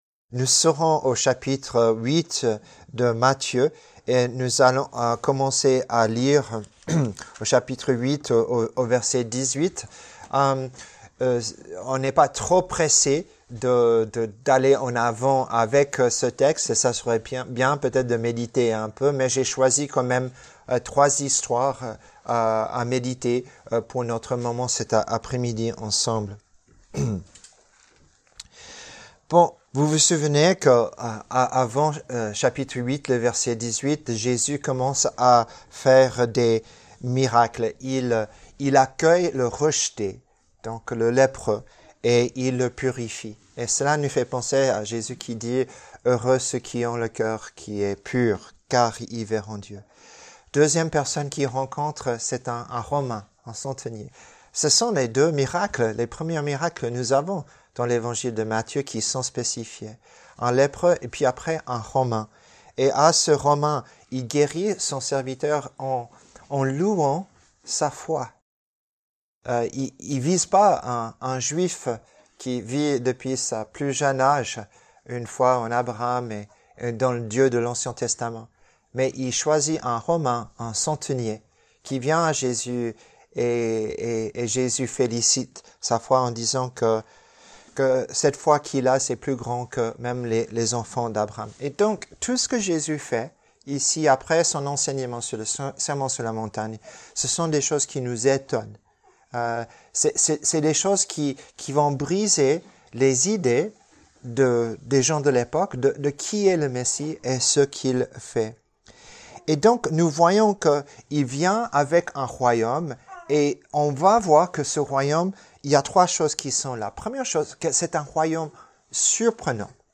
Les messages audio de l'Église du Christ de Lausanne.